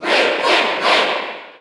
Crowd cheers (SSBU)
Link_&_Toon_Link_Cheer_English_PAL_SSBU.ogg